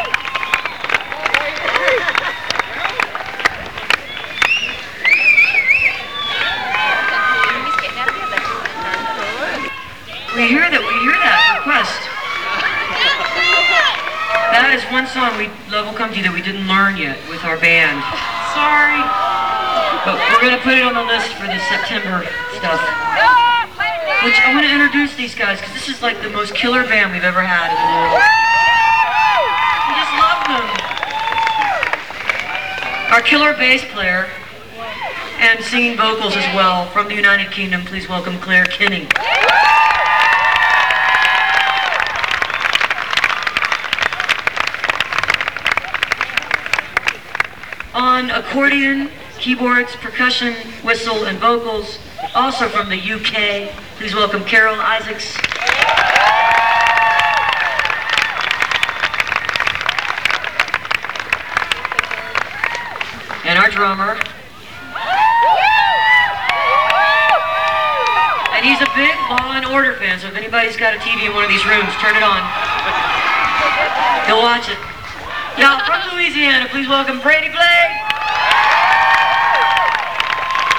lifeblood: bootlegs: 2002-07-18: humphrey's by the bay - san diego, california
09. band introductions (1:20)